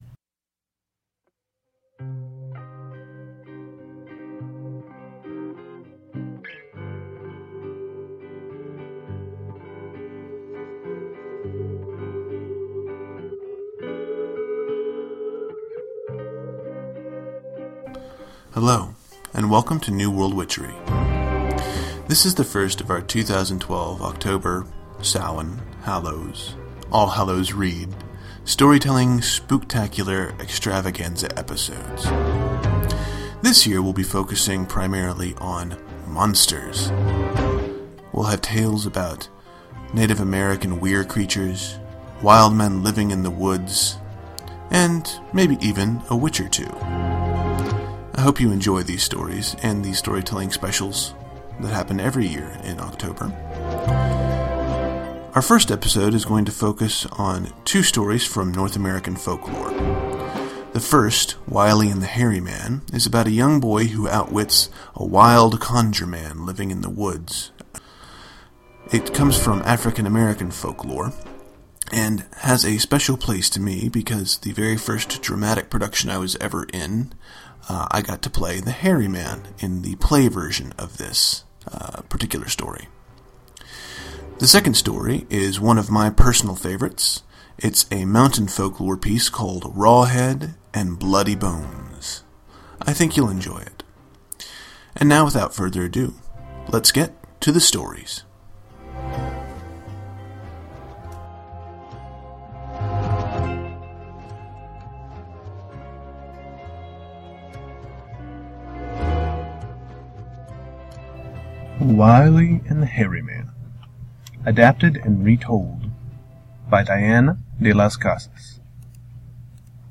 Summary In our first Halloween storytelling episode, we look at two folktales: “WIley & the Hairy Man,” (as retold by Diane de las Casas) and “Raw Head & Bloody Bones” (as retold by S.E. Schlosser)